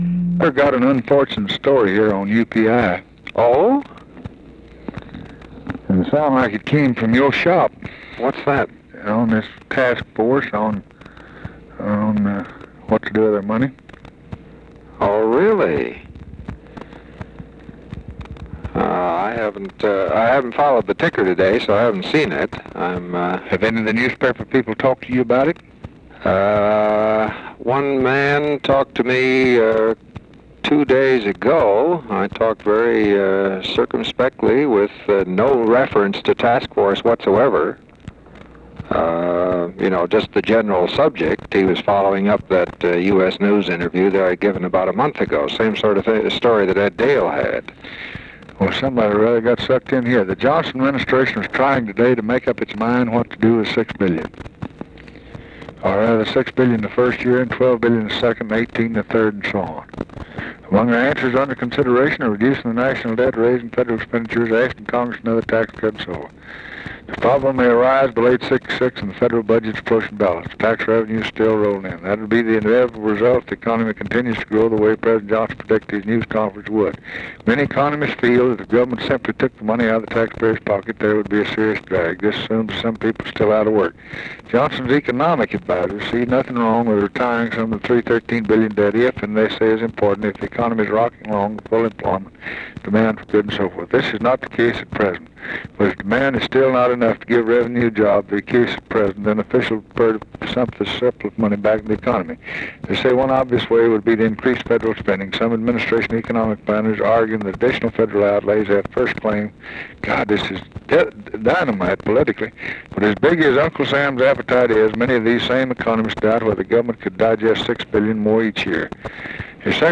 Conversation with WALTER HELLER and OFFICE CONVERSATION, July 11, 1964
Secret White House Tapes